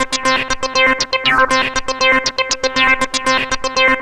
Track 16 - Arp 01.wav